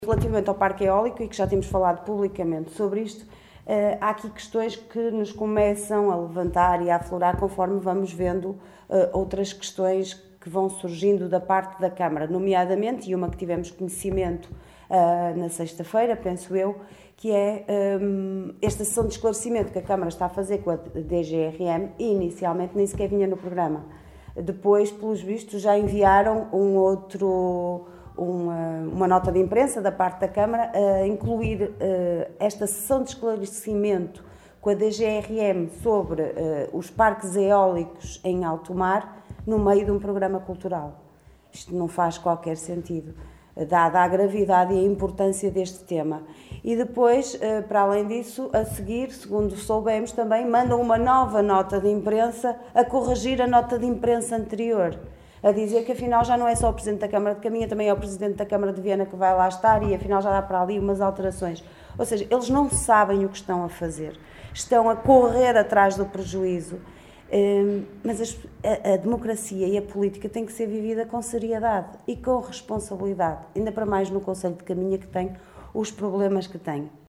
Liliana Silva na Conferência de imprensa desta manhã convocada pela OCP.